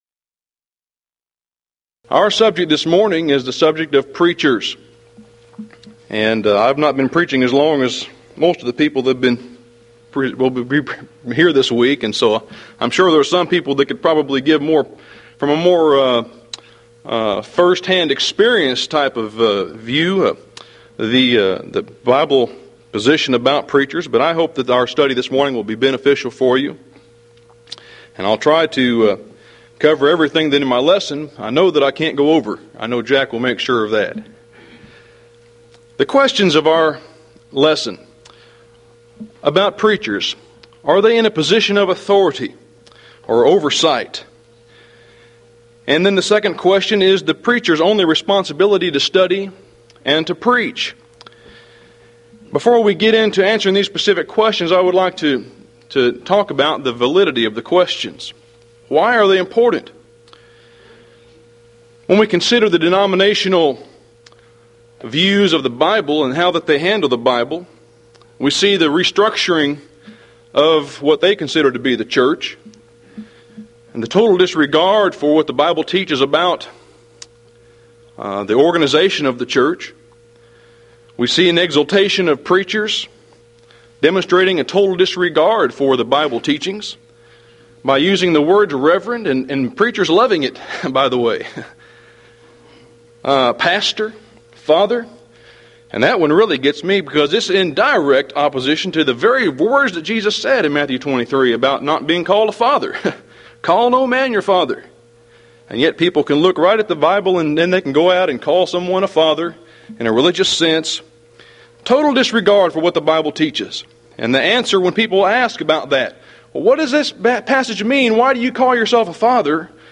Event: 1995 Mid-West Lectures Theme/Title: The Twisted Scriptures